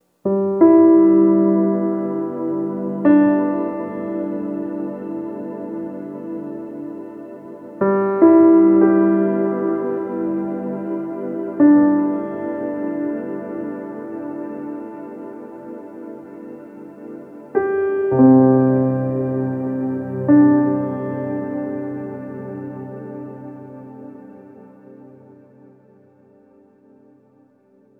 Reverb Piano 06.wav